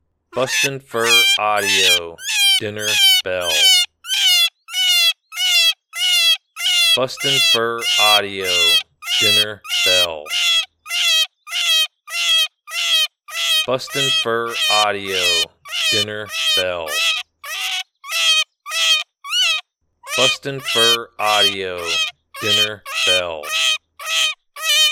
Adult Cottontail in distress. Excellent sound for calling predators, year around.